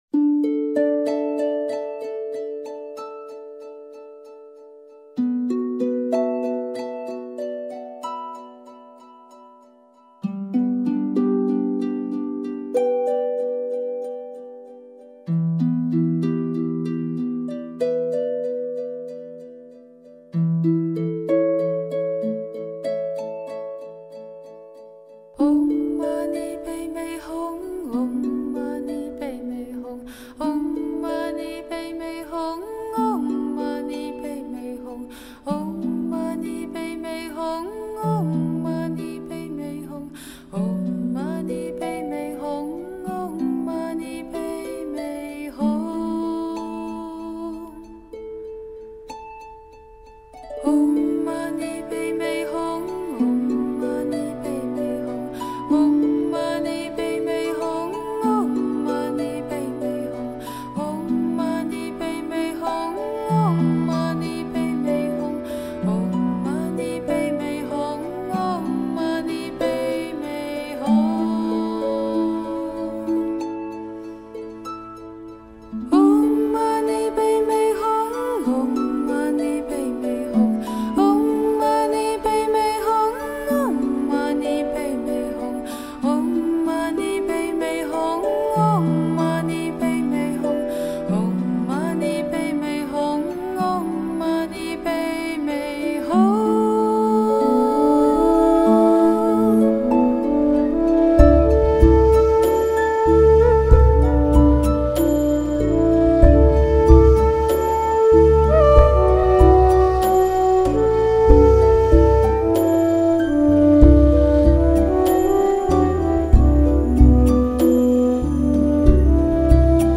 融合New Age的灵性与冥想音乐的神性